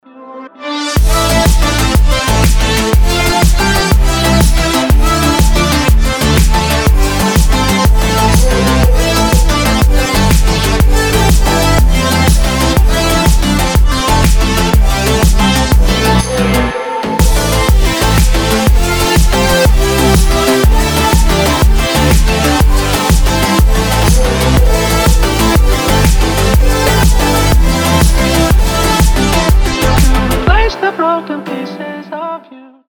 Electronic
EDM
future house
энергичные